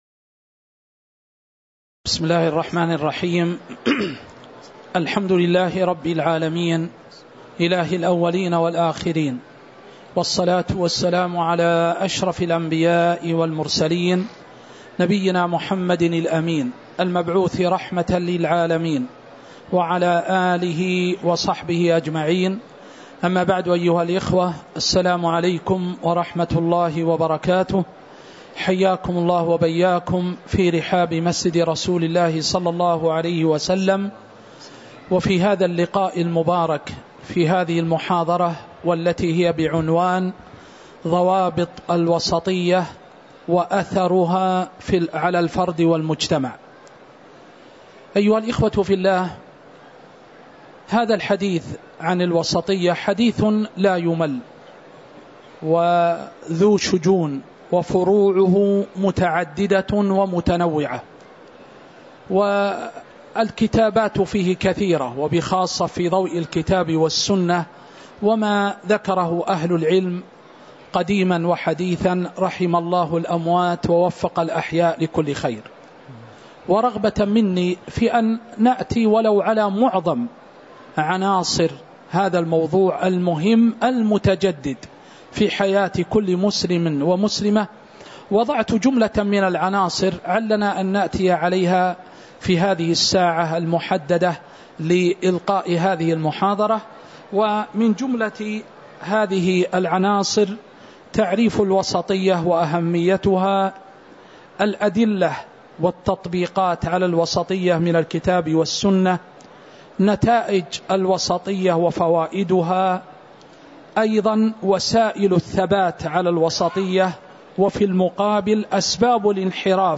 محاضرة ضوابط الوسطية وأثرها على الفرد والمجتمع وفيها: تعريف الوسطية وأهميتها، والأدلة عليها من الوحي، ونتائجها وفوائدها، أسباب الثبات عليها، والانحراف عنها
المكان: المسجد النبوي